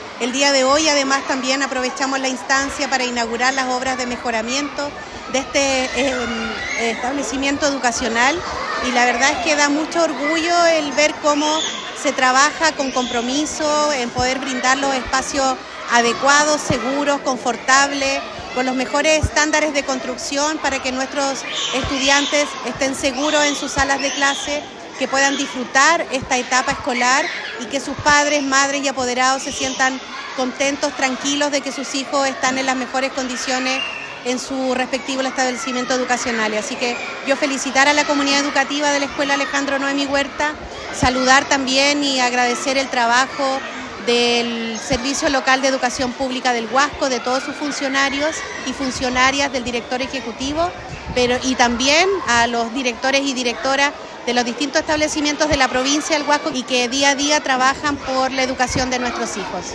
Karina-Zarate-Rodriguez-delegada-presidencial.mp3